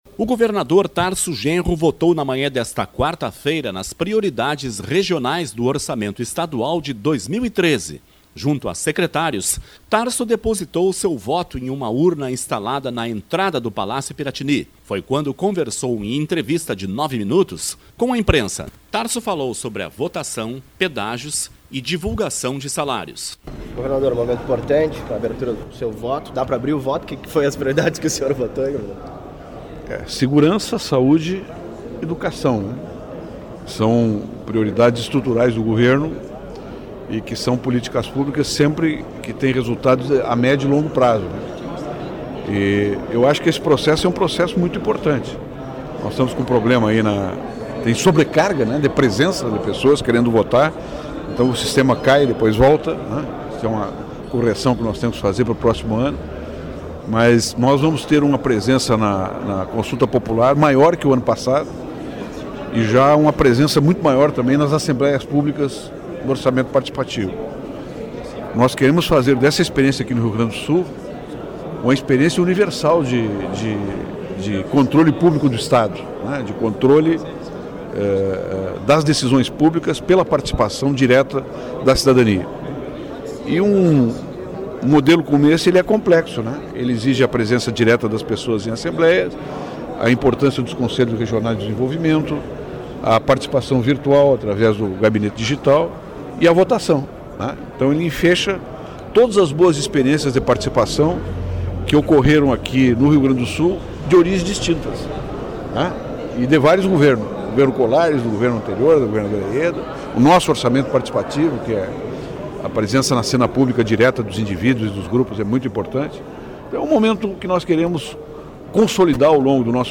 Tarso vota nas prioridades do Orçamento Estadual de 2013 e fala com a imprensa
O governador Tarso Genro votou na manhã desta quarta-feira (4) nas prioridades regionais do Orçamento Estadual de 2013. Junto a secretários, Tarso depositou seu voto em uma urna instalada na entrada do Palácio Piratini.